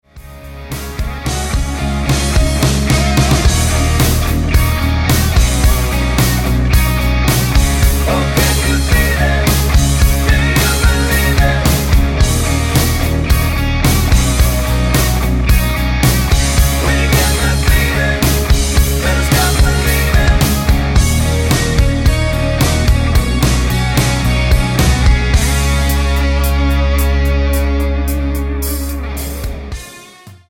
--> MP3 Demo abspielen...
Tonart:Db mit Chor